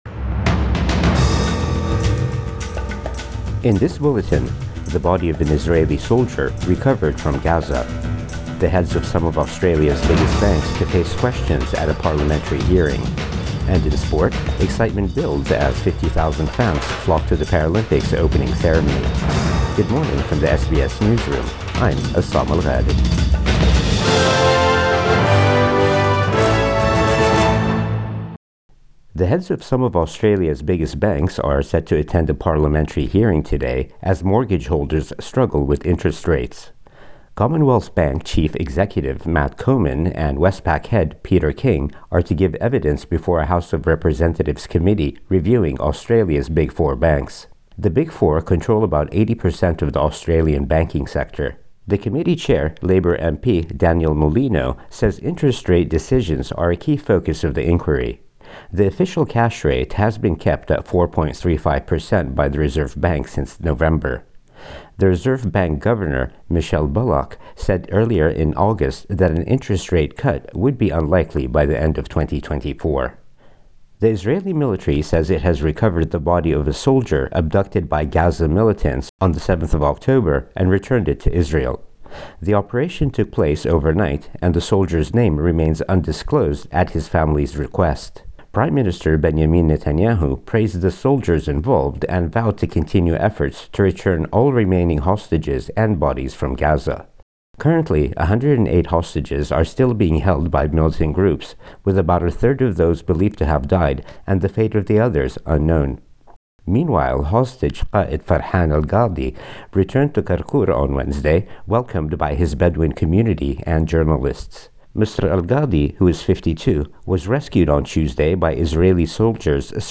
Morning News Bulletin 29 August 2024